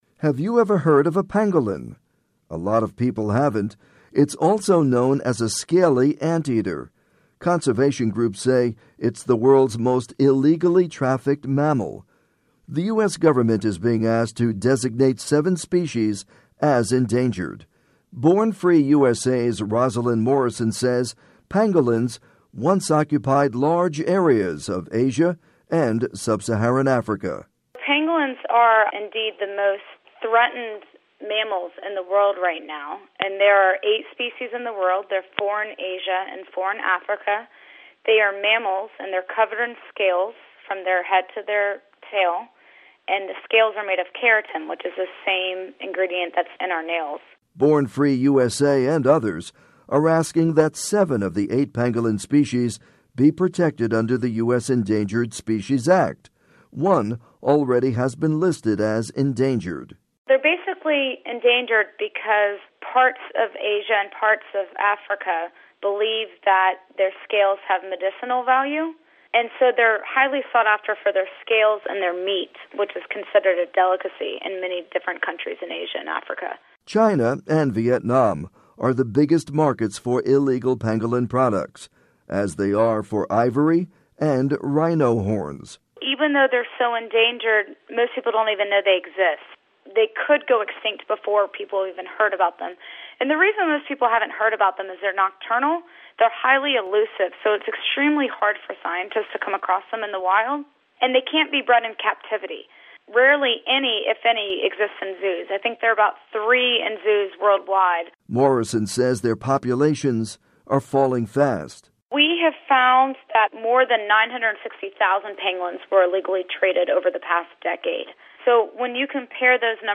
report on endangered pangolins